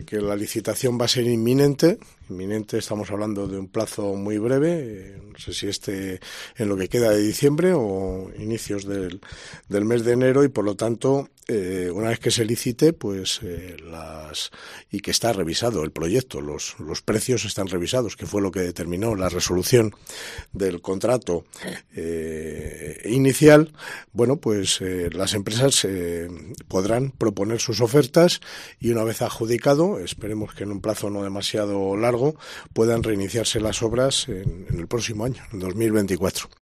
José Luis Sanz Merino, delegado territorial de la Junta, sobre el centro de salud Segovia-IV
La licitación de lo que queda del proyecto de construcción del centro de salud de Nueva Segovia se efectuará este mismo mes de diciembre o a principios de 2024. Lo aseguraba en los micrófonos de COPE, el delegado territorial de la Junta, José Luis Sanz Merino.